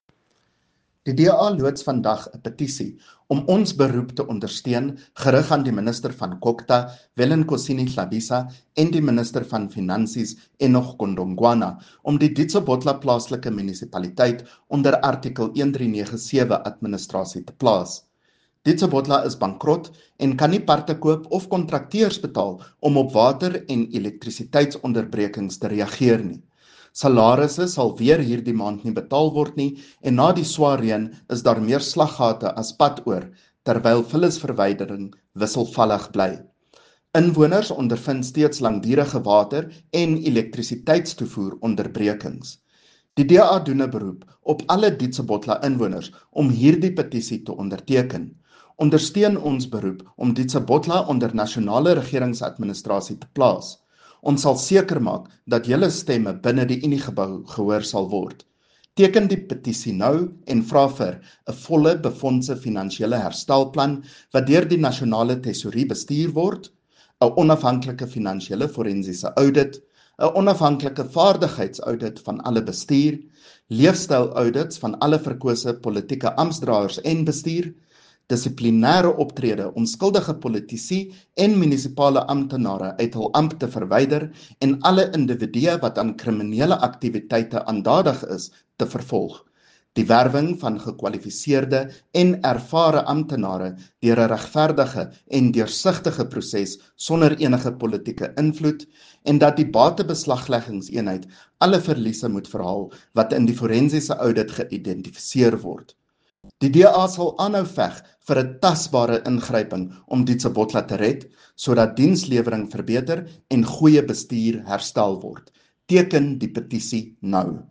Note to Broadcasters: Please find linked soundbites in
Afrikaans by CJ Steyl MPL.